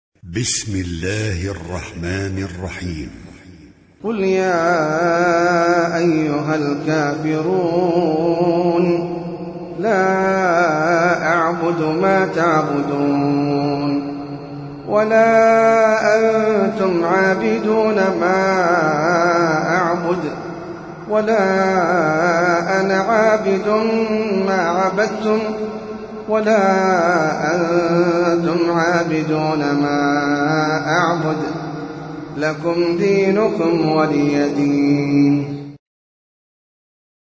Sûrat Al-Kafiroon (The Disbelievers) - Al-Mus'haf Al-Murattal (Narrated by Hafs from 'Aasem)
high quality